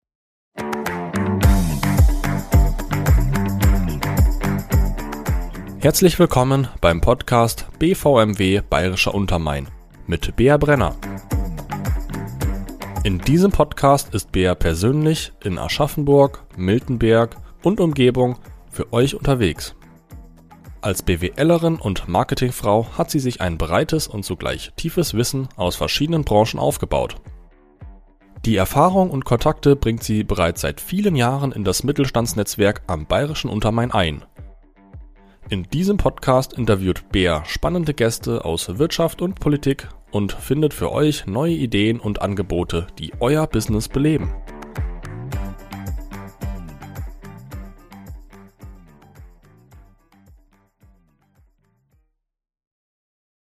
Trailer - BVMW Bayerischer Untermain